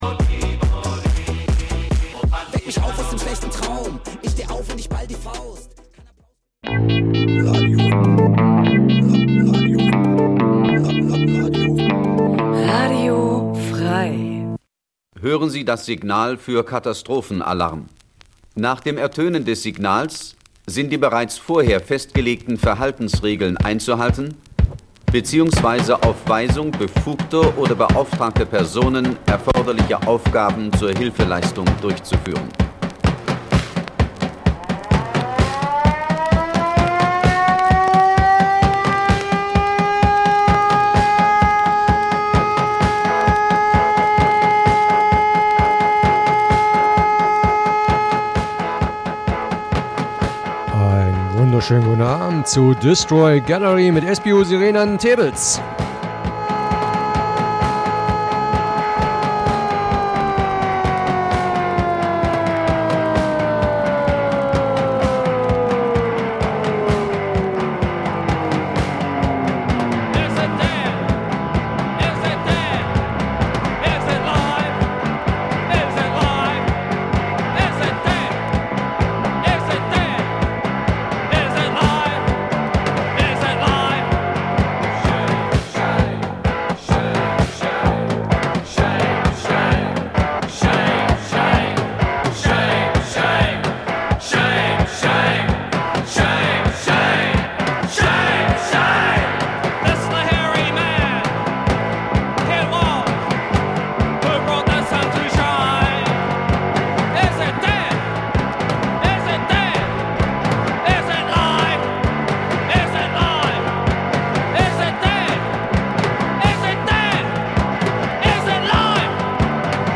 Punk '77 - '79 Dein Browser kann kein HTML5-Audio.